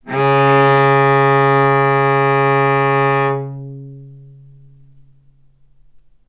vc-C#3-ff.AIF